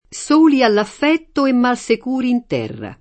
S1li all aff$tto e mmalSek2ri in t$rra] (Giusti)